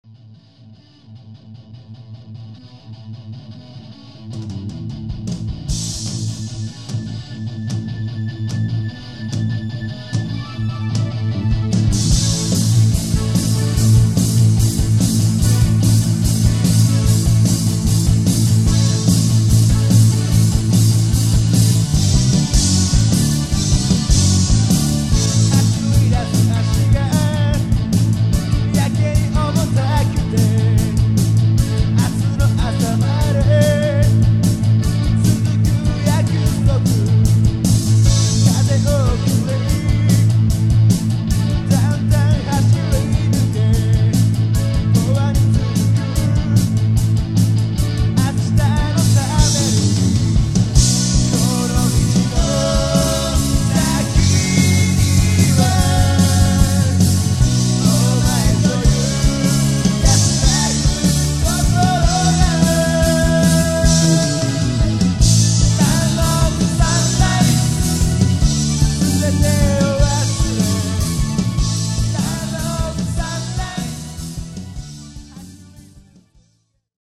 ストレートなポップナンバーだ。
この曲の最後でのメンバー全員の歓声が印象的だ。